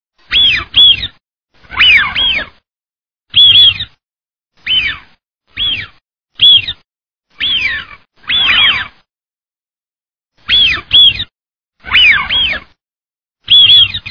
Canto del ánade silbón
cantoAnadeSilbon.mp3